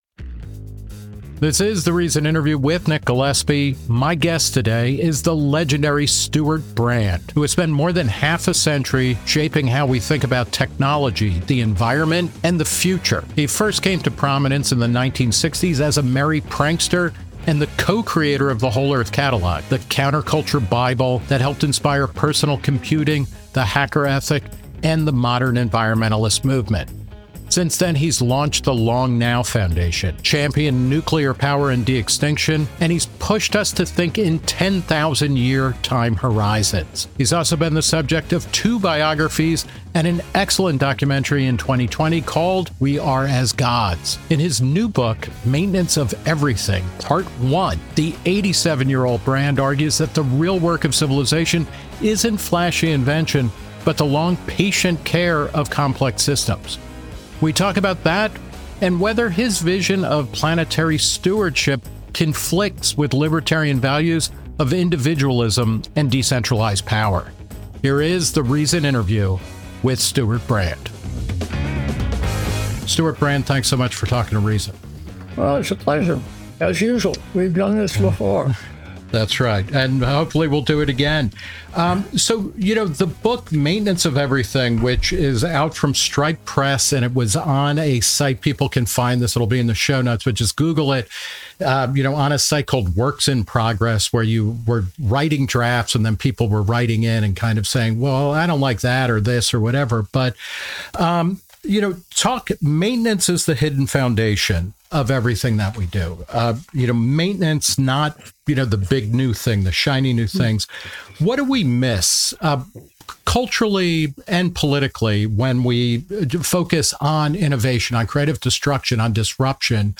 The Reason Interview
He talks with Nick Gillespie about what that means and whether his vision of planetary stewardship conflicts with libertarian values of individualism, creative destruction, and decentralized power.